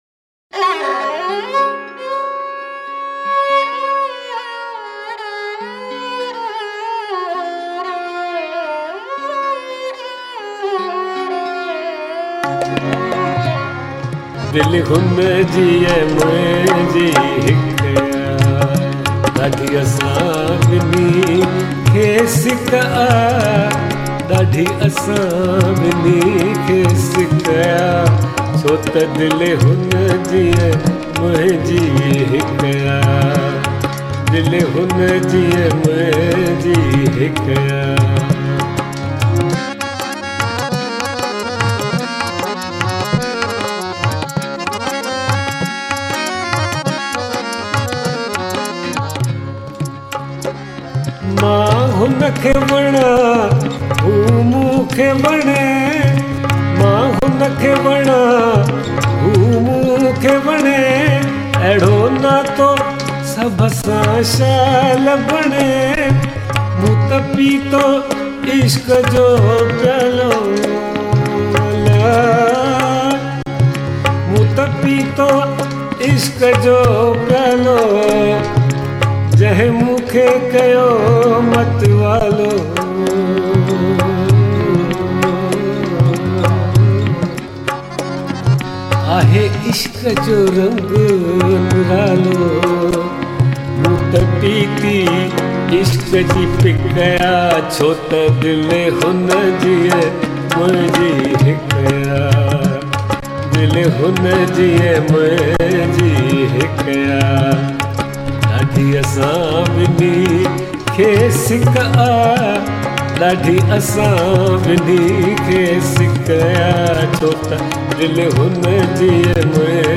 soothing voice